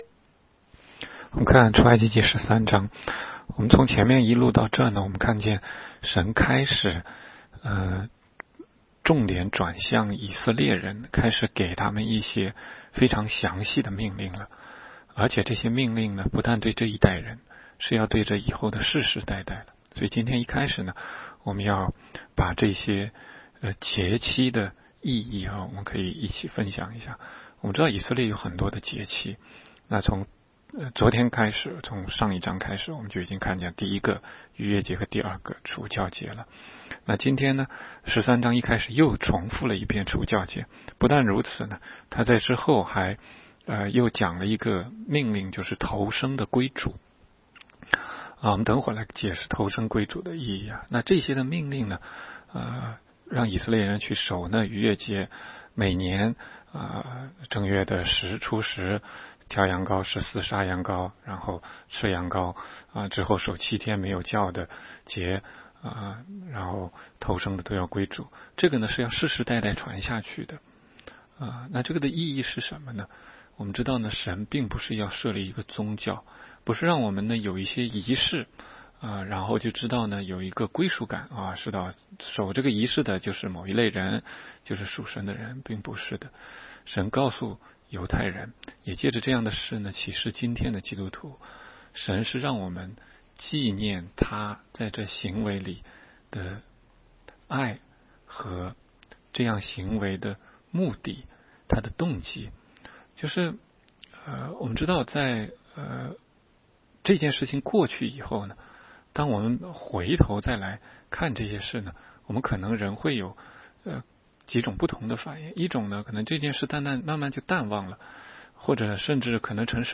16街讲道录音 - 每日读经-《出埃及记》13章